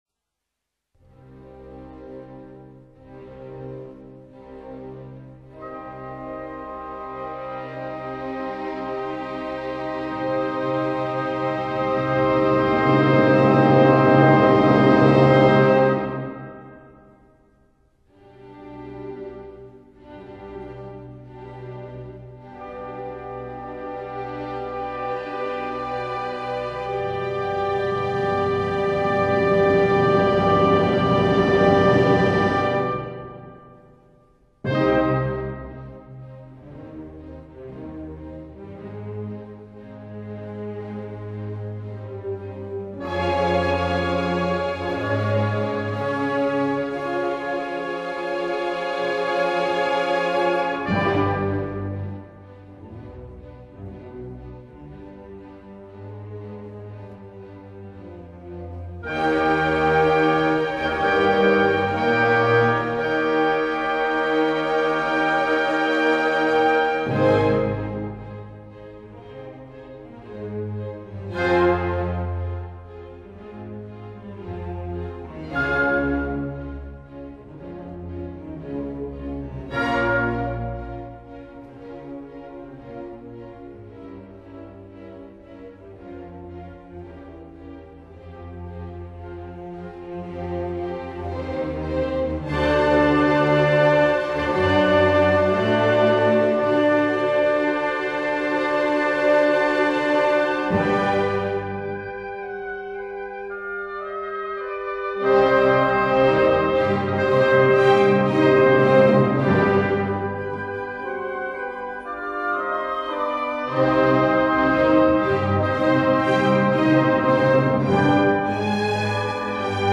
韋瑟是丹麥古典主義時期最重要的作曲家，他的音樂風格類似維也納古典樂派，具有和諧，優美，雅致的特點。